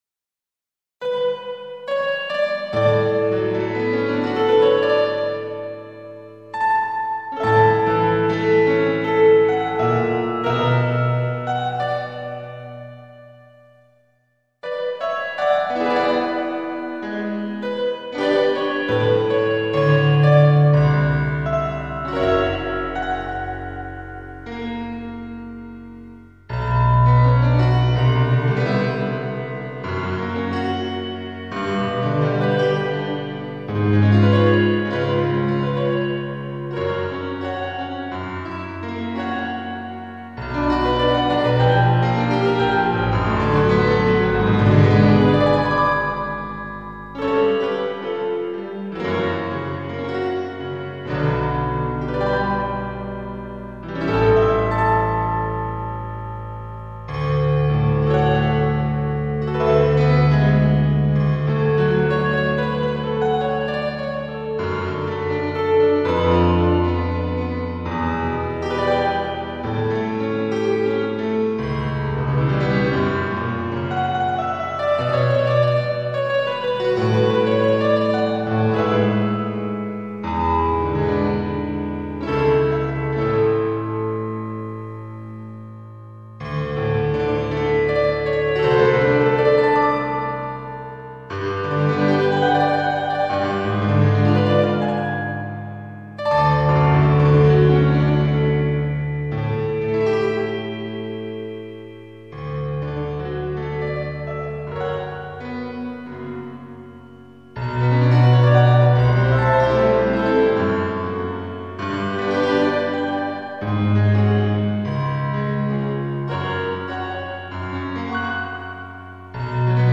Но без партии для голоса.